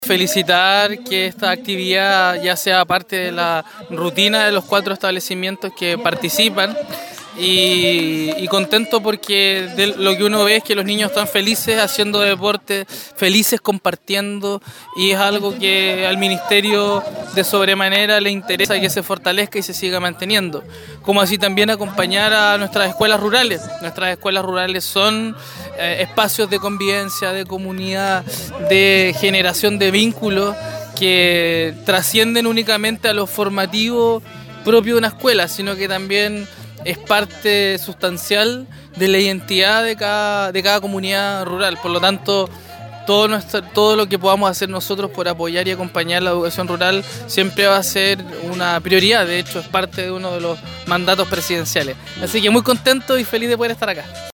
Registro de la actividad realizada el jueves 24 de noviembre en el sector Antilhue, comuna de Lanco.
audio-seremi.mp3